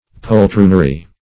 Search Result for " poltroonery" : Wordnet 3.0 NOUN (1) 1. abject pusillanimity ; The Collaborative International Dictionary of English v.0.48: Poltroonery \Pol*troon"er*y\, n. [F. poltronnerie; cf. It. poltroneria.]
poltroonery.mp3